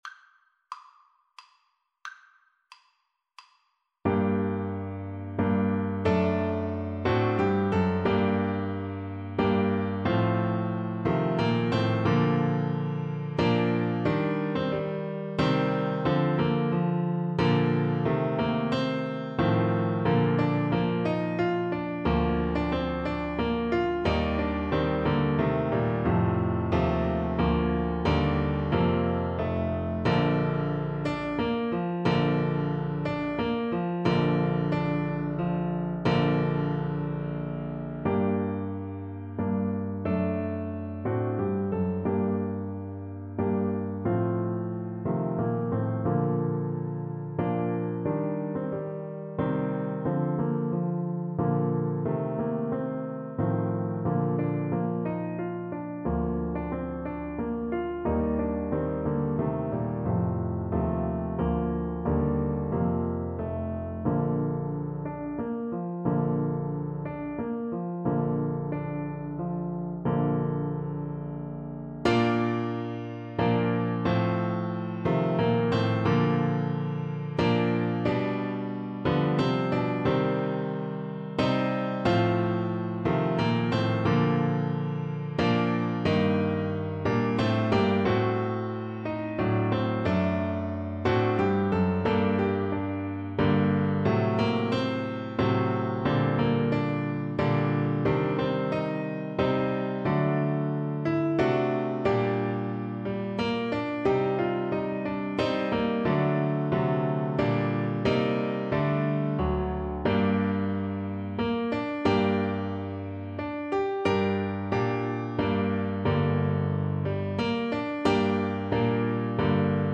Play (or use space bar on your keyboard) Pause Music Playalong - Piano Accompaniment Playalong Band Accompaniment not yet available transpose reset tempo print settings full screen
Tuba
G minor (Sounding Pitch) (View more G minor Music for Tuba )
=90 IV: Andante
3/4 (View more 3/4 Music)
Classical (View more Classical Tuba Music)